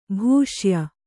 ♪ bhūṣya